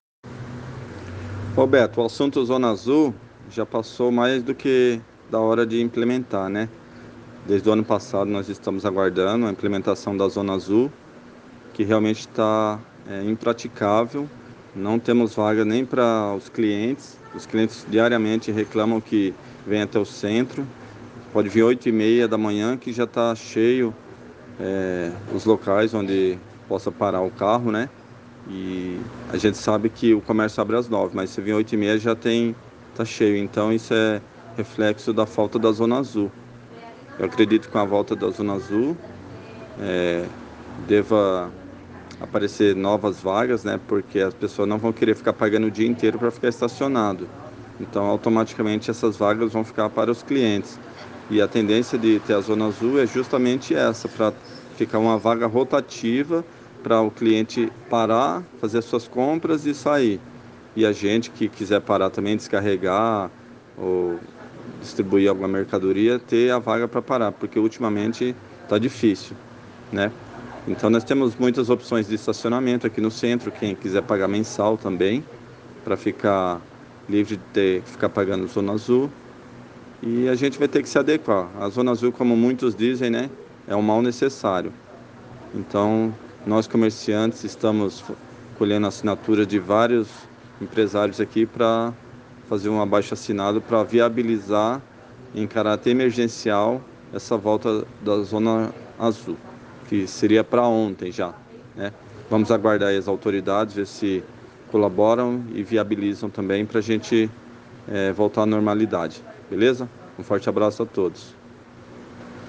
Sobre o assunto, nossa reportagem falou por telefone